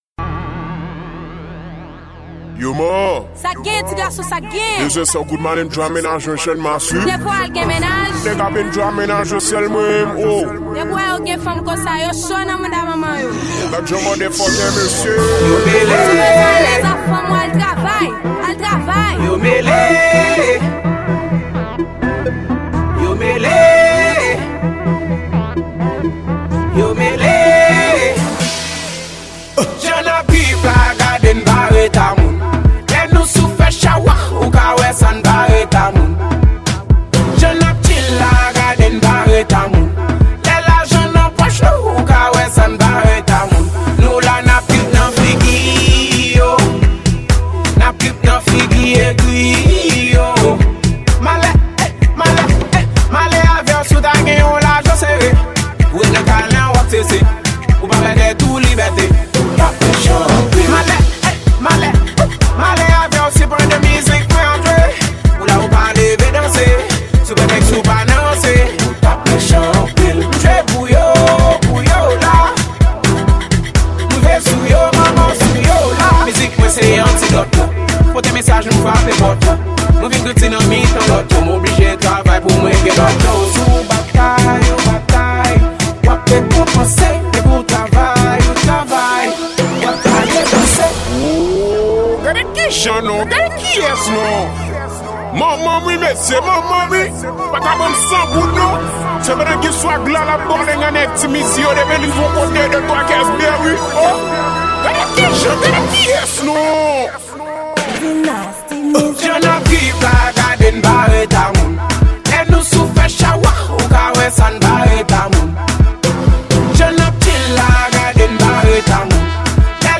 Genre: Afro.